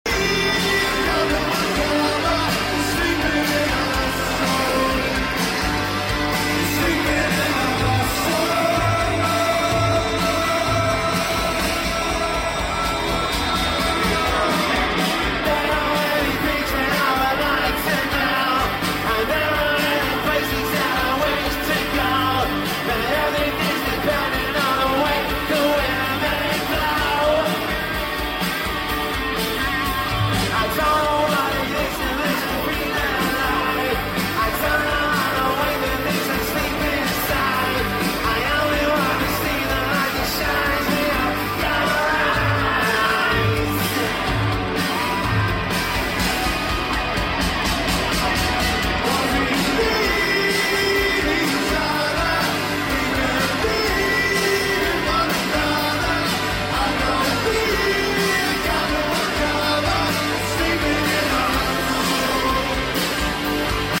live in Chicago, USA